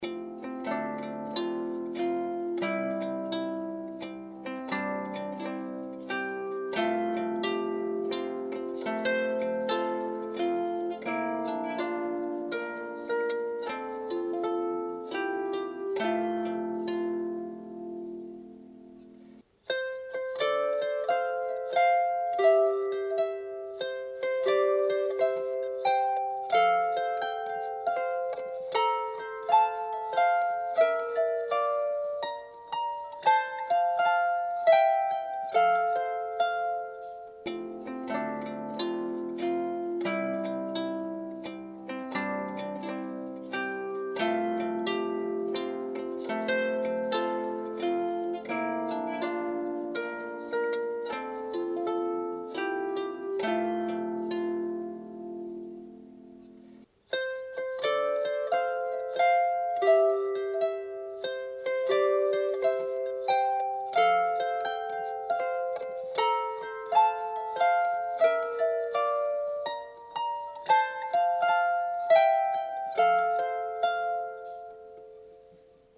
on double strung harp